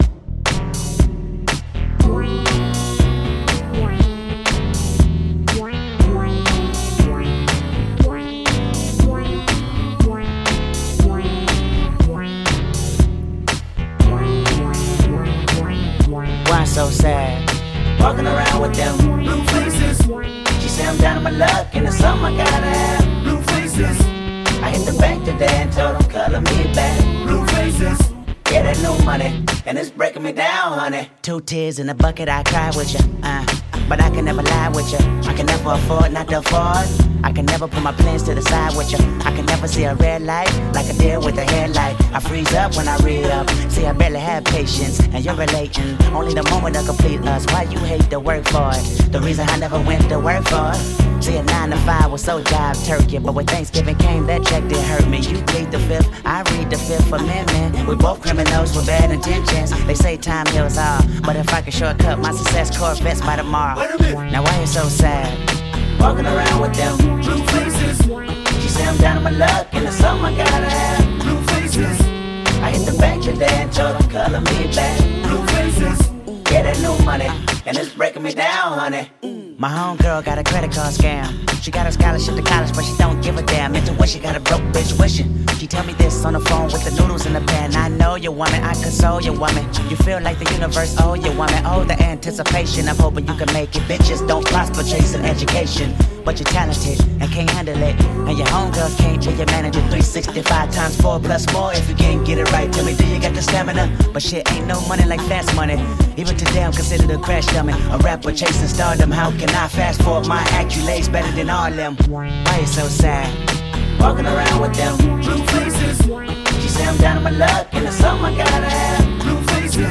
It's way more loose, raw, and enjoyable.